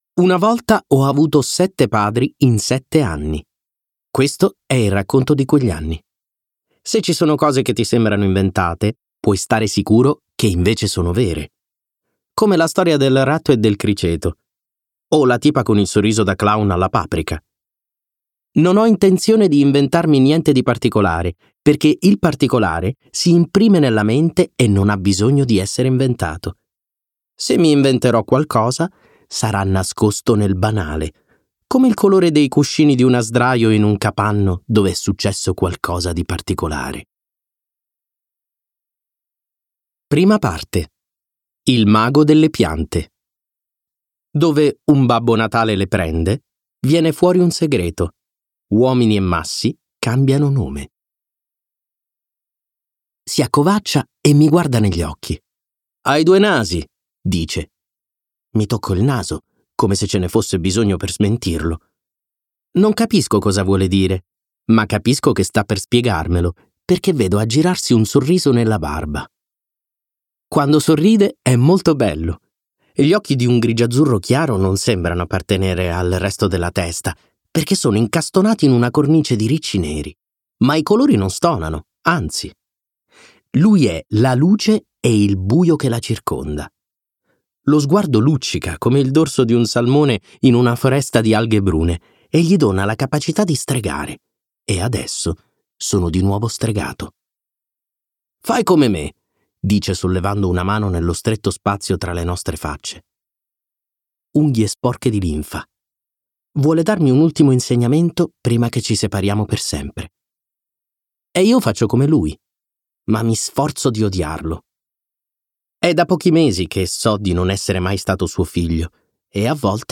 Versione audiolibro integrale